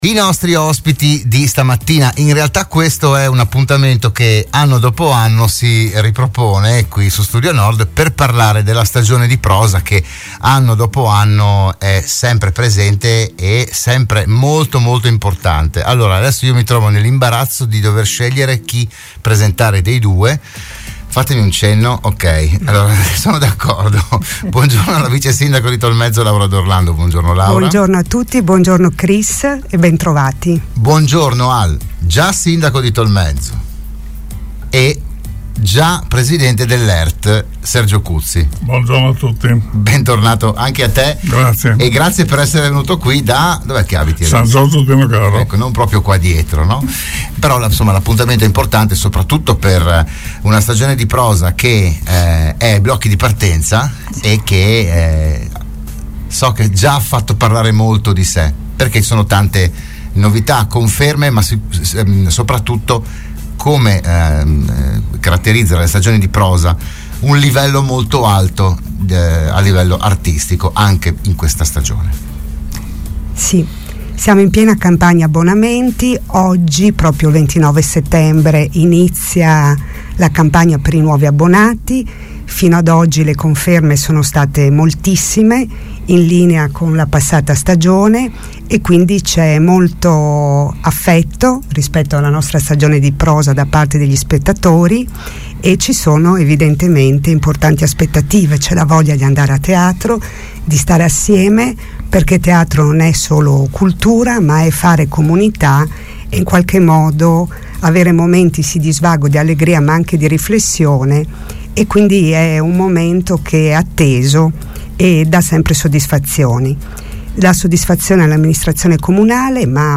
Della stagione teatrale di Tolmezzo si è parlato a “ RadioAttiva “, la trasmissione di Radio Studio Nord